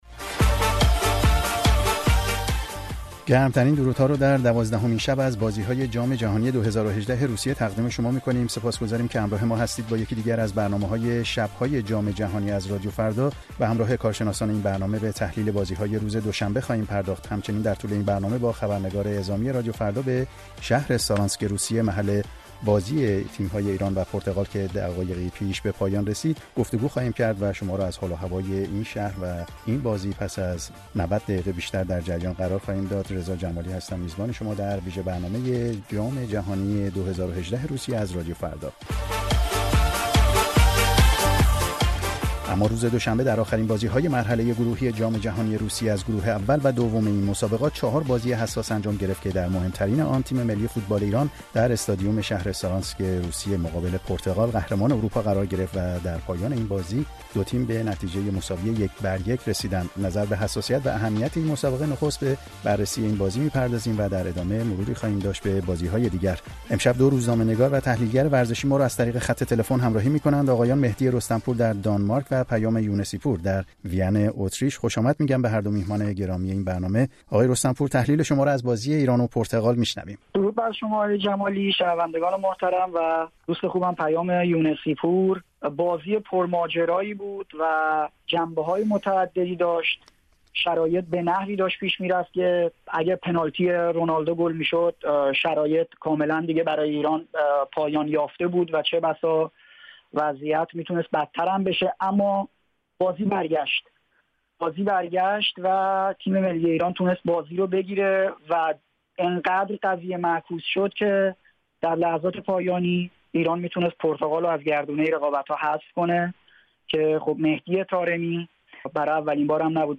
میزگرد بررسی بازی‌های جام جهانی فوتبال در روسیه ۲۰۱۸